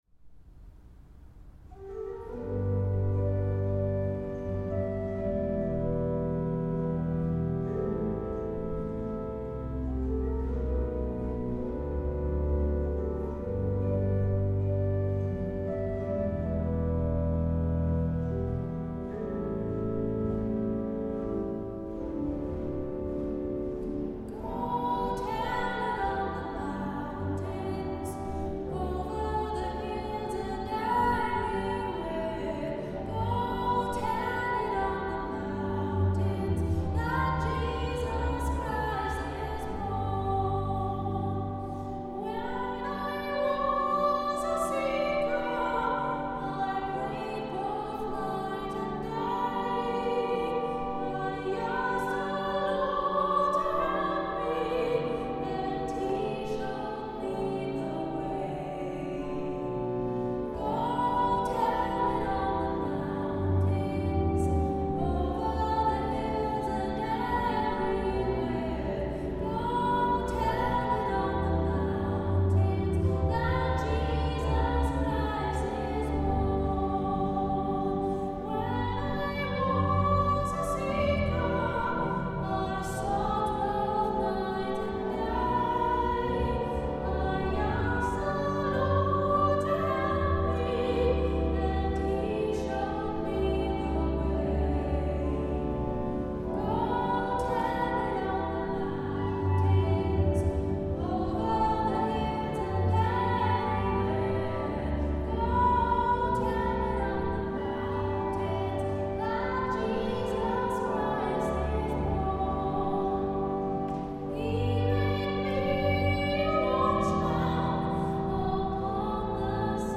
Jahresschlussgottesdienst 2012
Sopran
Orgel